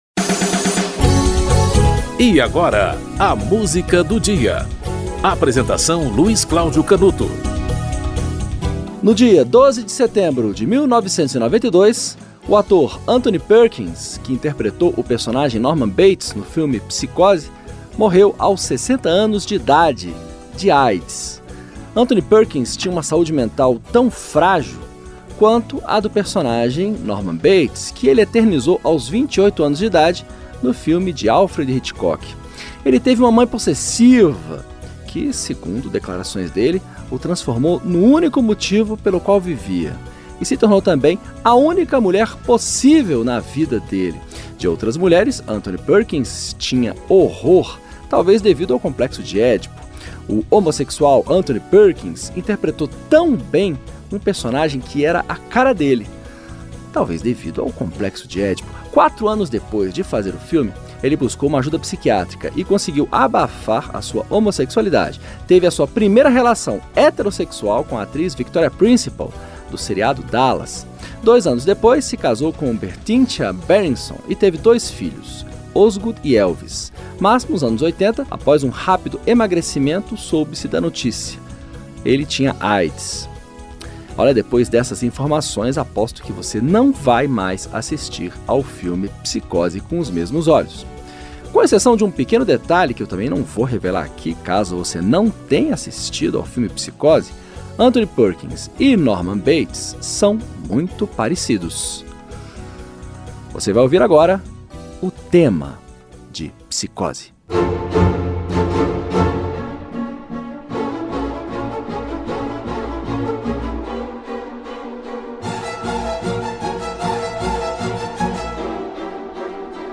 Produção e apresentação-